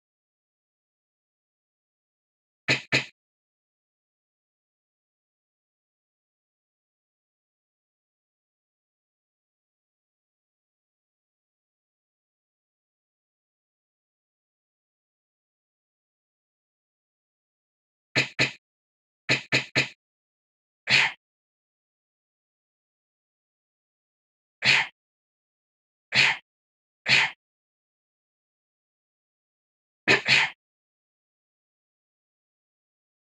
自室の壁を殴るだけ、という意味不明なもの。